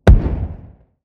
SmokeExplode.wav